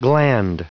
Prononciation du mot gland en anglais (fichier audio)
Prononciation du mot : gland